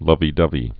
(lŭvē-dŭvē)